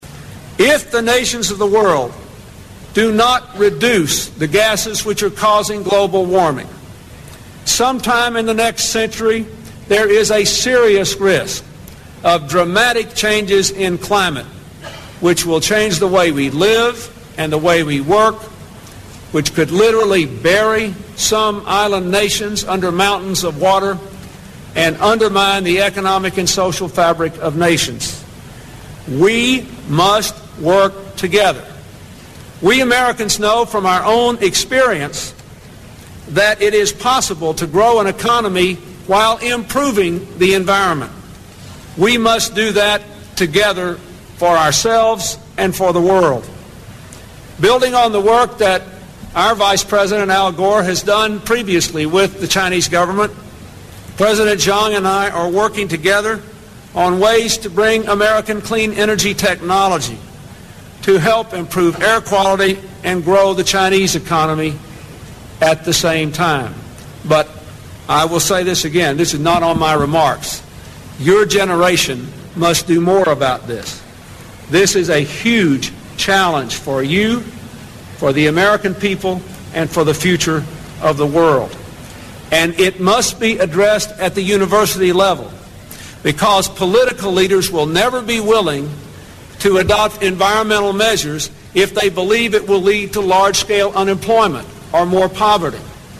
名校励志英语演讲 115:21世纪的挑战(9) 听力文件下载—在线英语听力室
借音频听演讲，感受现场的气氛，聆听名人之声，感悟世界级人物送给大学毕业生的成功忠告。